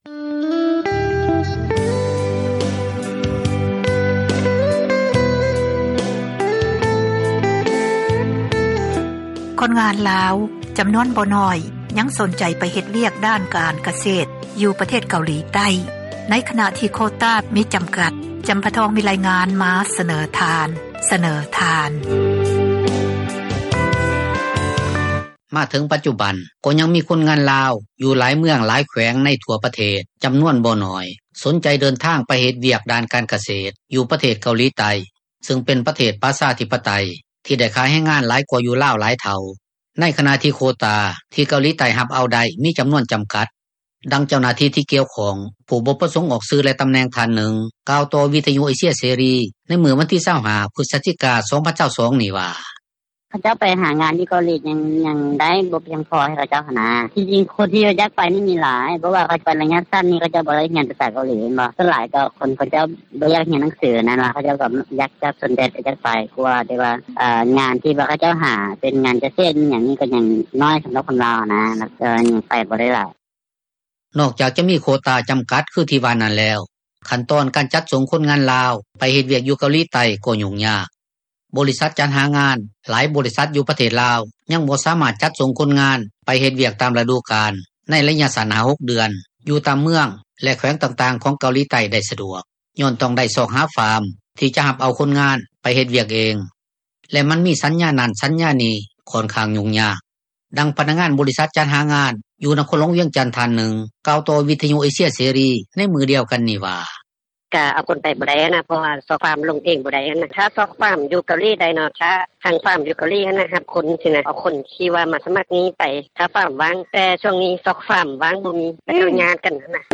ດັ່ງພະນັກງານ ບໍຣິສັດຈັດຫາງານ ຢູ່ນະຄອນຫລວງວຽງຈັນ ທ່ານນຶ່ງ ກ່າວຕໍ່ວິທຍຸ ເອເຊັຽ ເສຣີ ໃນມື້ດຽວກັນນີ້ວ່າ:
ດັ່ງຊາວນະຄອນຫລວງວຽງຈັນ ທີ່ສົນໃຈເດີນທາງ ໄປເຮັດວຽກຢູ່ປະເທດເກົາຫລີໃຕ້ ຜູ້ນຶ່ງ ເວົ້າຕໍ່ວິທຍຸເອເຊັຽ ເສຣີໃນມື້ວັນທີ 25 ພຶສຈິການີ້ວ່າ:
ດັ່ງພະນັກງານ ບໍຣິສັດຈັດຫາງານ ຢູ່ນະຄອນຫລວງວຽງຈັນນາງນຶ່ງ ເວົ້າຕໍ່ວິທຍຸ ເອເຊັຽ ເສຣີ ໃນມື້ດຽວກັນນີ້ວ່າ: